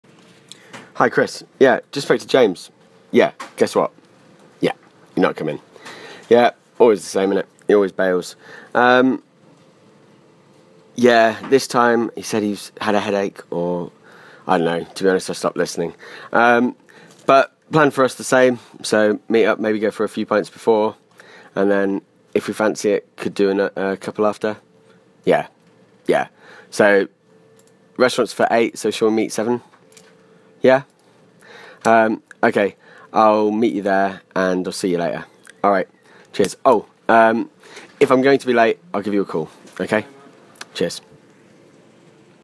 This one seeks to differ though in its focus on future forms. There is also a focus pronunciation, intonation in the first listening and connected speech in the second.